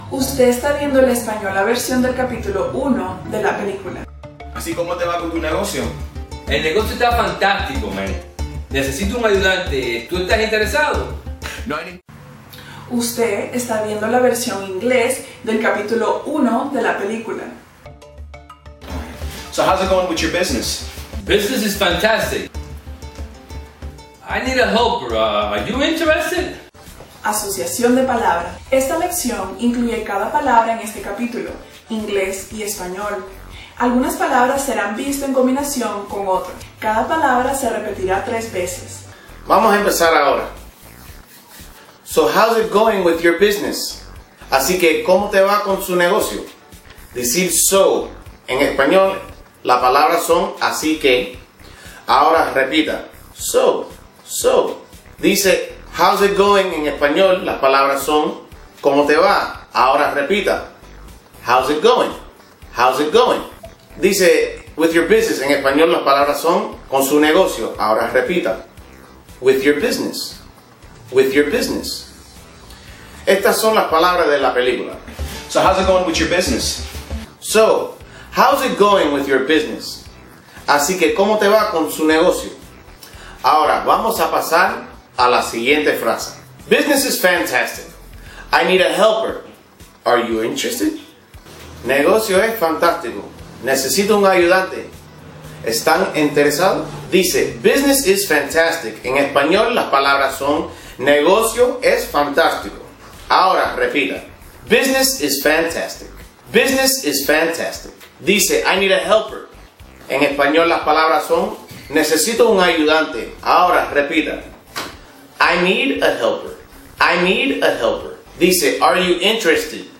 Aqu� est� el audio mp3 de la lecci�n r�pida de hoy.